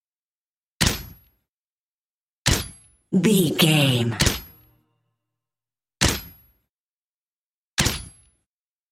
Pistol Firing with Silencer 05 | VGAME
Filled with 5 sounds(44/16 wav.) of Pistol Firing(Five single shots) with silencer.
Sound Effects
Adobe Audition, Zoom h4
muted
silenced